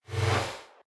poleWoosh.ogg